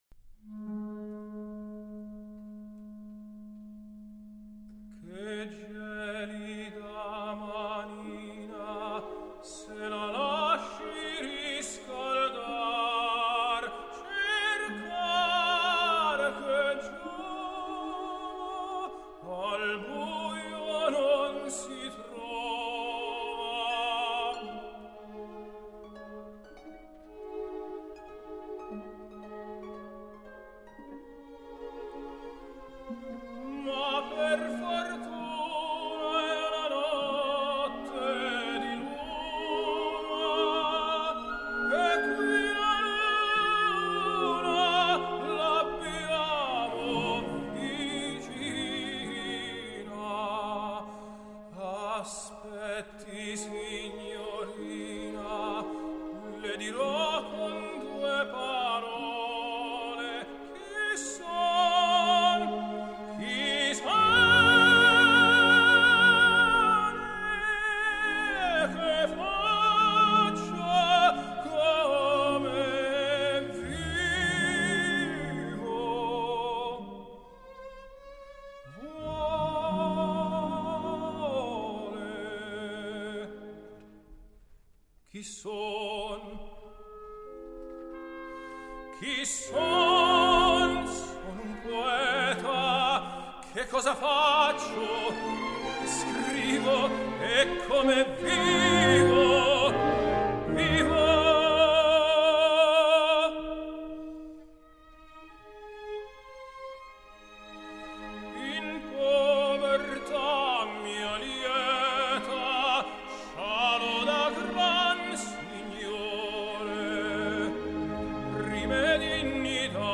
类别：古典音乐